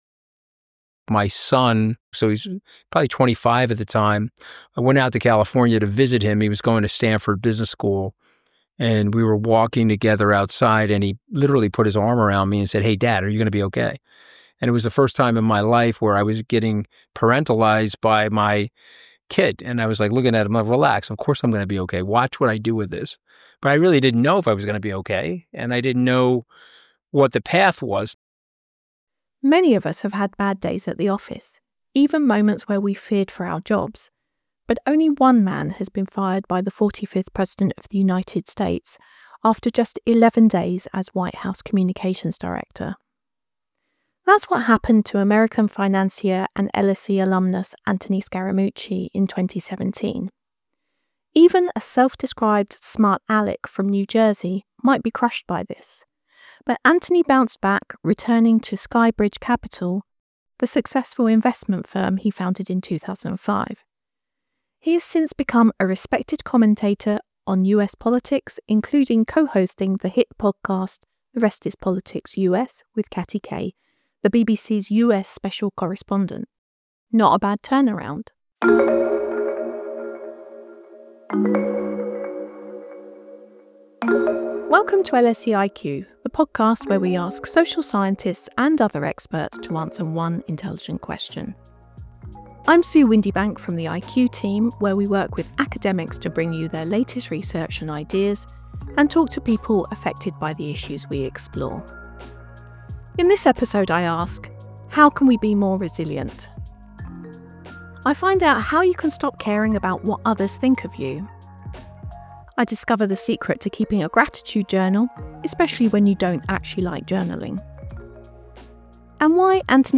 speaks to Anthony Scaramucci about overcoming public failure